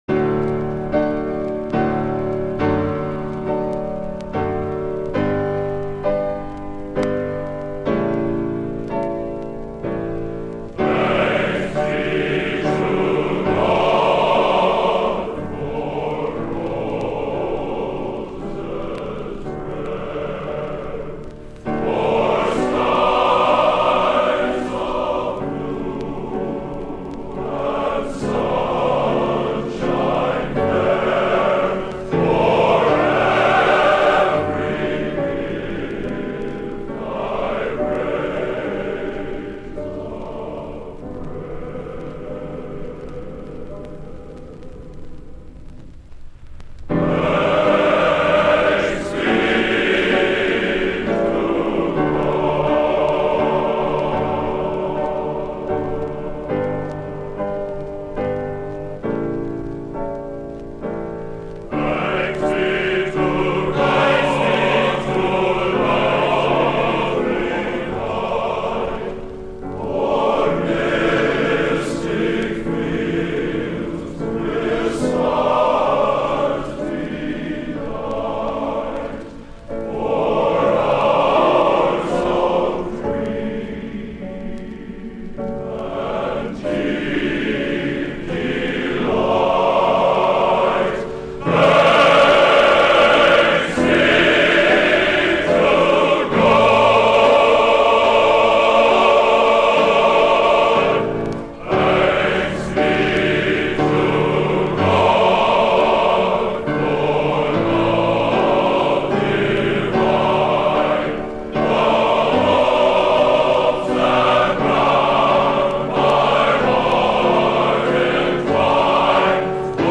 Location: West Lafayette, Indiana
Genre: Sacred | Type: